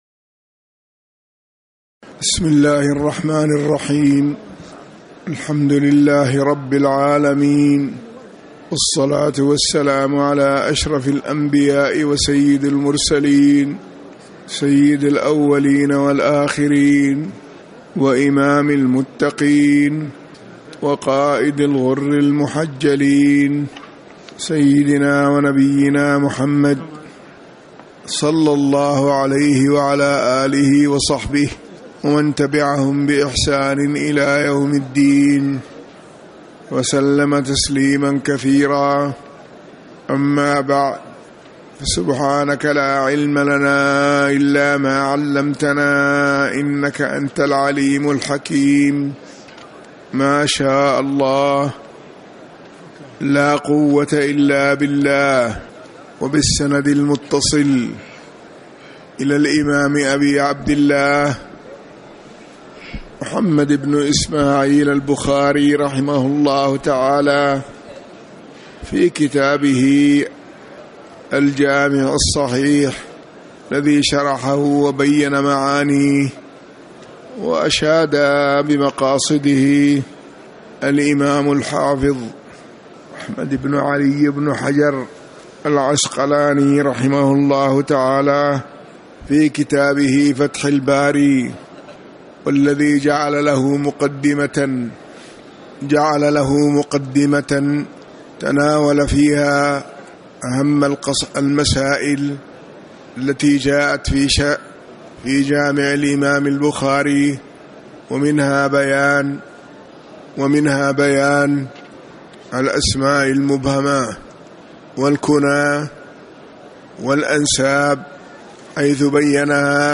تاريخ النشر ٢٤ ربيع الأول ١٤٤٠ هـ المكان: المسجد النبوي الشيخ